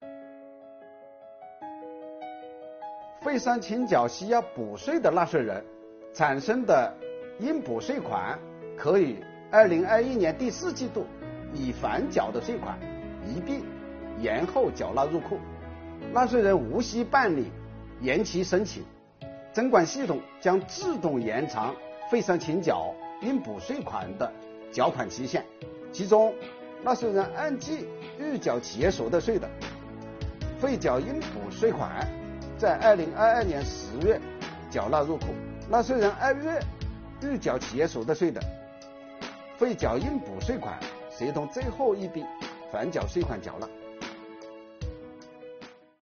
本期课程国家税务总局征管和科技发展司副司长付扬帆担任主讲人，对制造业中小微企业缓缴税费政策解读进行详细讲解，确保大家能够及时、便利地享受政策红利。